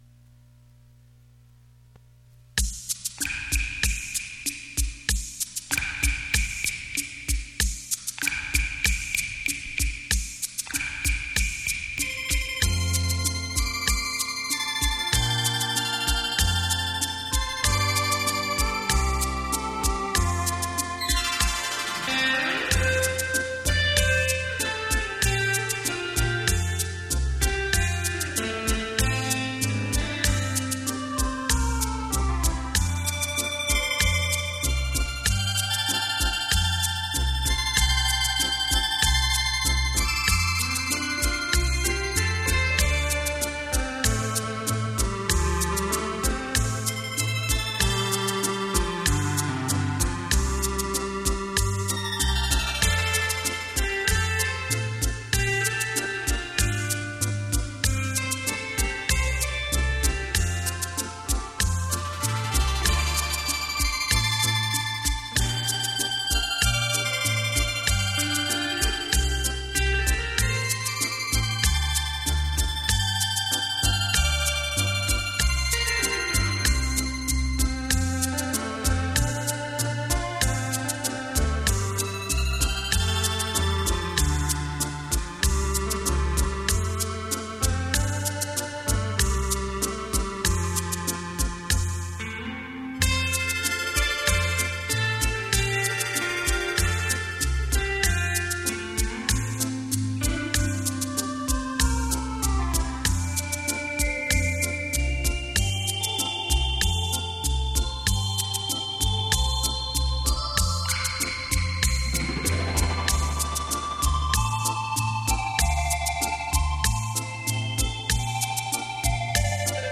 优美的旋律 清脆的音感